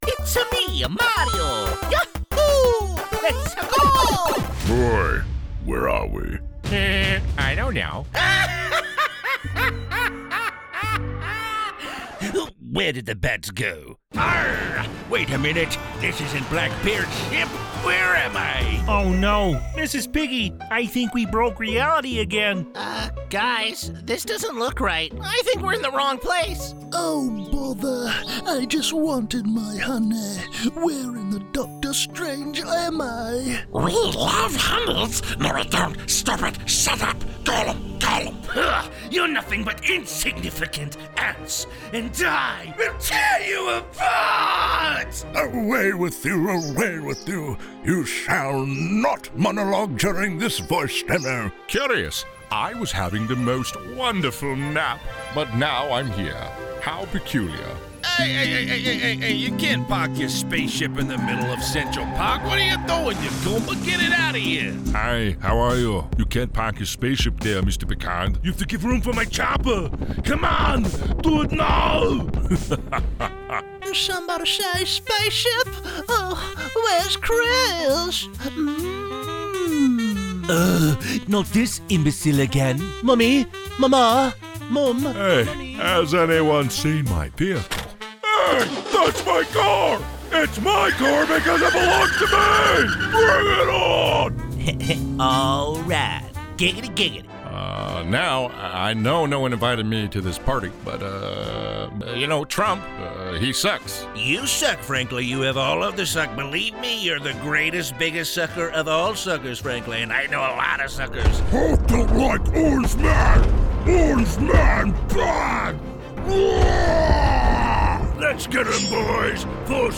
The Voice Realm represents versatile American and Canadian voice over talent with North American accents suited to international voice castings from small jobs to worldwide campaigns.